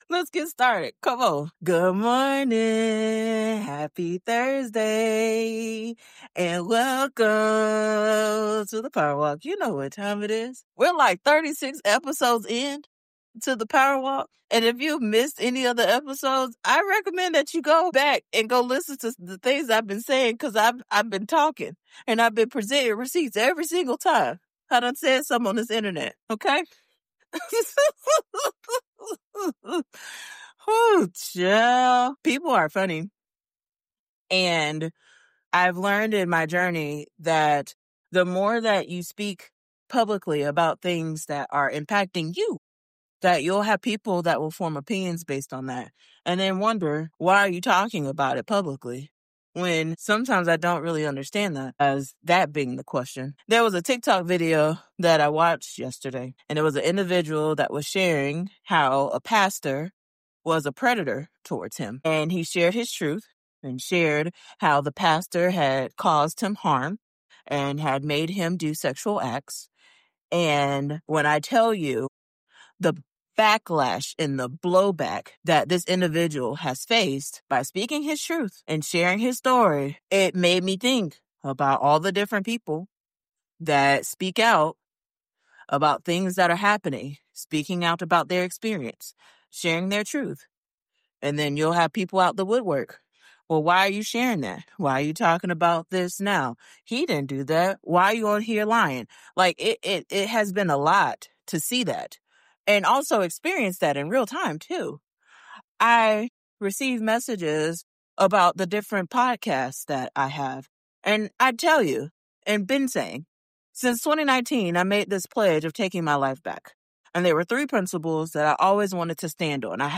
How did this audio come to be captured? Recorded during real walks